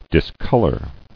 [dis·col·or]